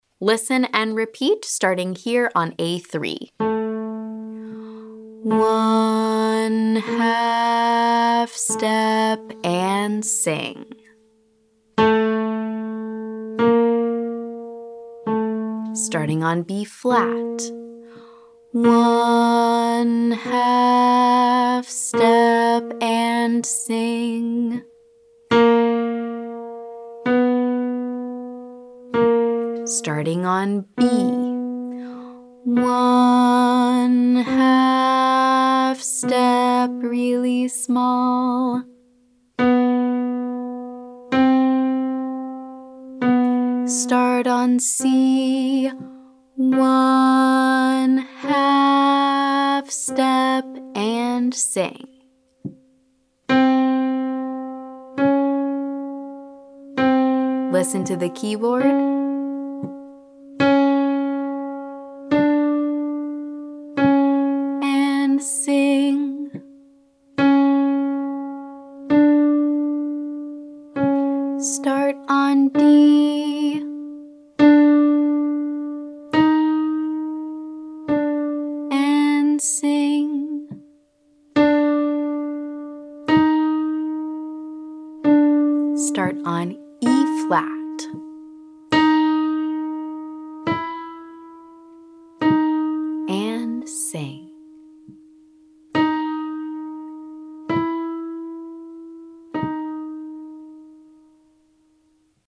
This interval is smaller than the whole step.
Exercise - half step, listen & repeat